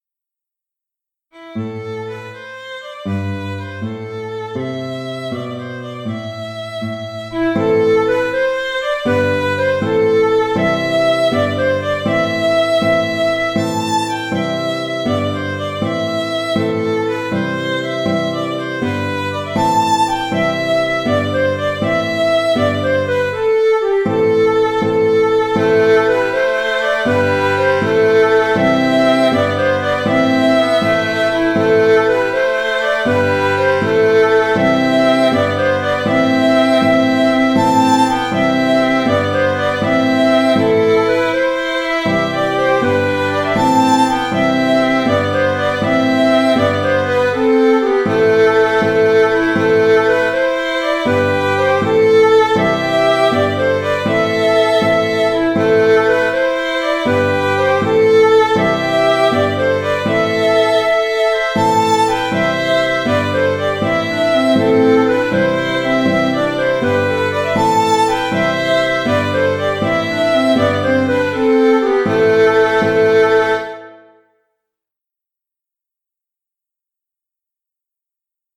Les Gabiers J’ai composé ce morceau comme une chanson de marins.
Je propose deux contrechants, à alterner, selon le choix des musiciens..